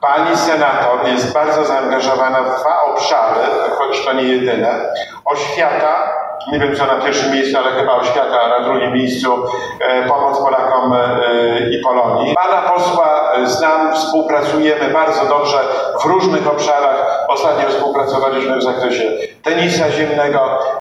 Marszałek Senatu na spotkaniu wyborczym w Ełku
Marszałek Senatu, Stanisław Karczewski, przyjechał do Ełku na spotkanie z wyborcami. W środę (28.08.) odpowiadał na pytania, związane z remontami dróg lokalnych, subwencją oświatową czy ochroną tradycyjnych wartości rodzinnych.